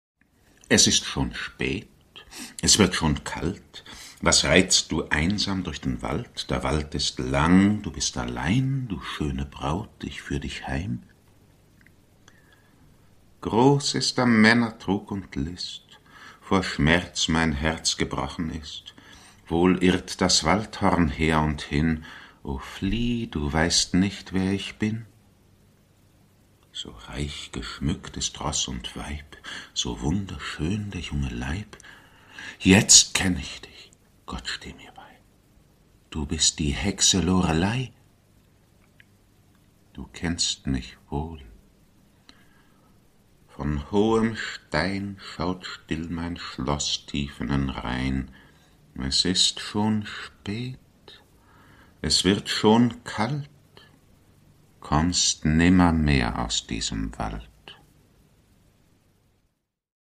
Produkttyp: Hörspiel-Download
Gelesen von: Anna Thalbach, Klausjürgen Wussow, Gerd Wameling, Peter Lühr, Mathias Wieman, Wanja Mues, Gert Westphal